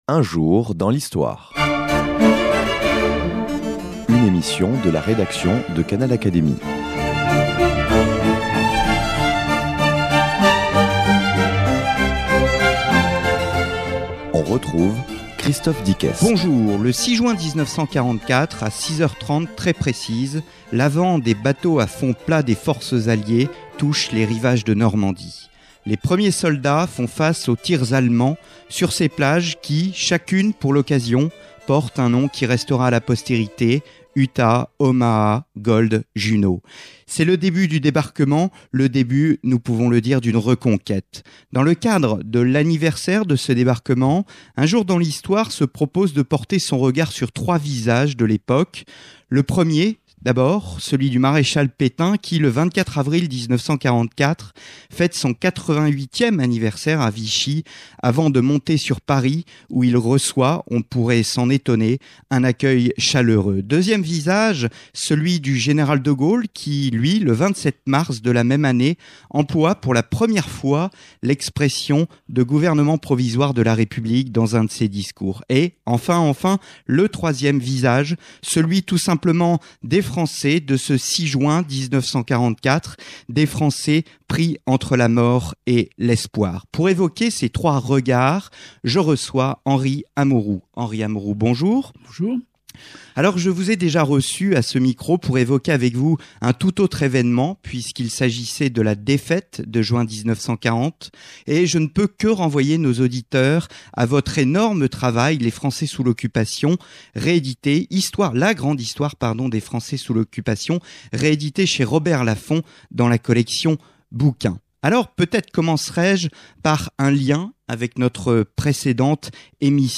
Henri Amouroux (1920-2007), journaliste, historien, nous retrace le débarquement des troupes alliées en Basse-Normandie. Mais en traçant rapidement le portrait de plusieurs "personnages" : le maréchal Pétain, le général de Gaulle, et les "Français du 6 juin", sujet qui lui tenait à coeur, il démontre en quoi ce débarquement fut "un coup de génie".